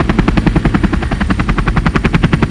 use_gyrocopter.wav